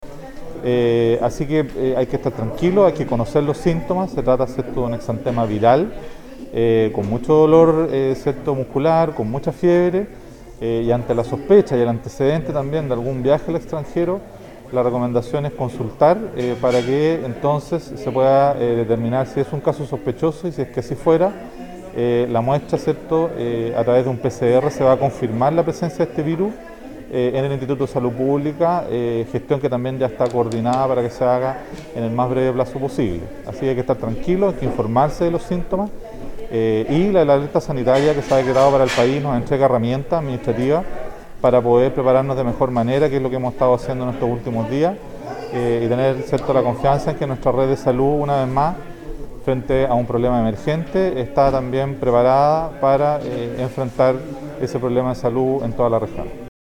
Junto con ello el personero llamó a la comunidad a estar tranquilos y conocer los síntomas de la enfermedad.